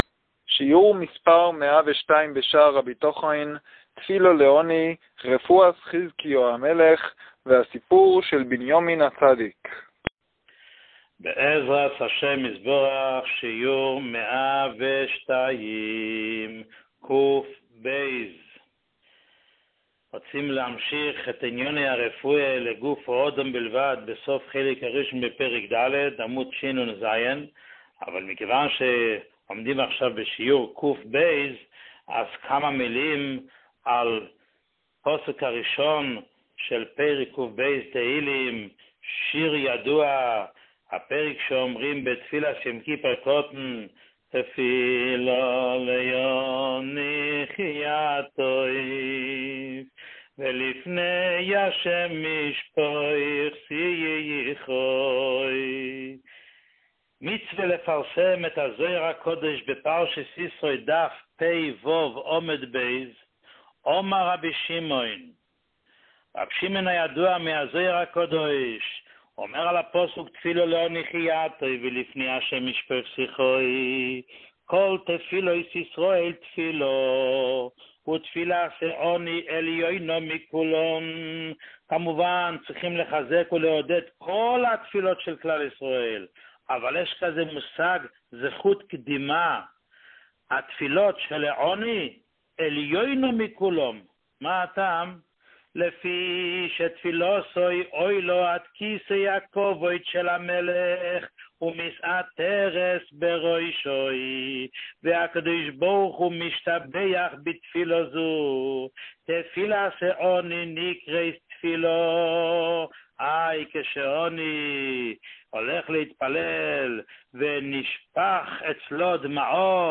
שיעור 102